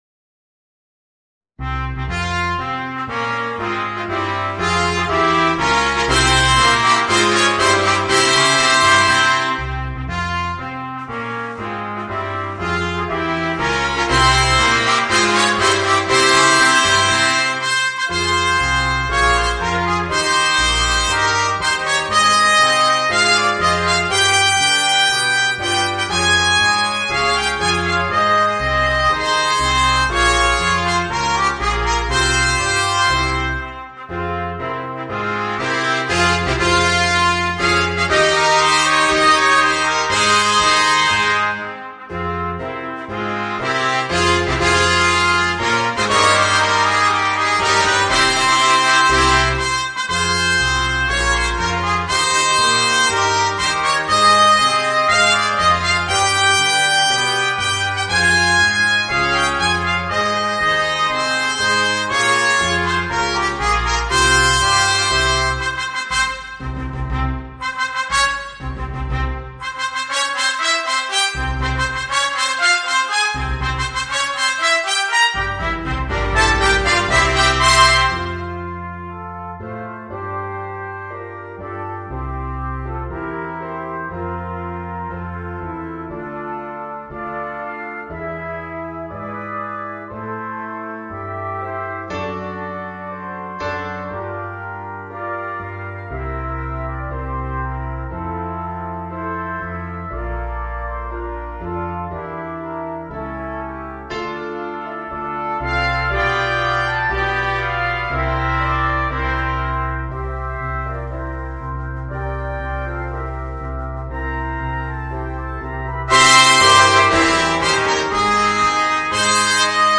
Voicing: 4 Trumpets and Piano